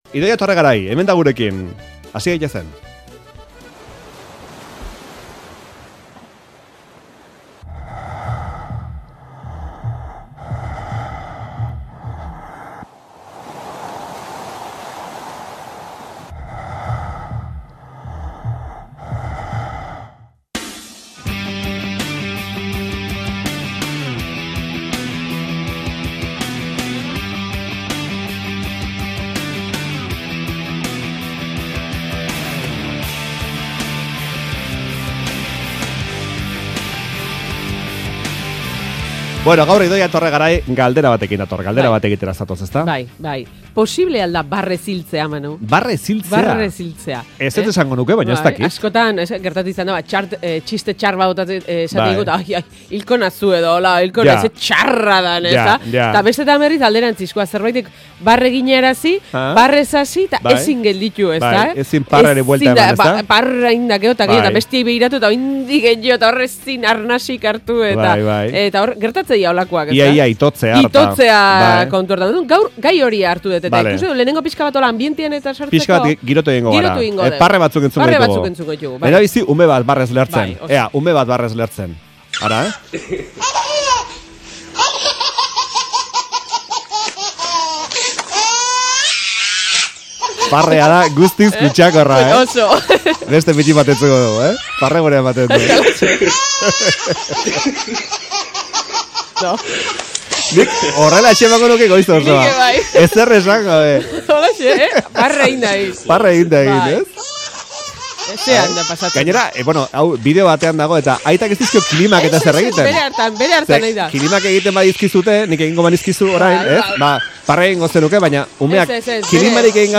Gaur ere halaxe ibili gara Euskadi Irratiko Faktoria saioan!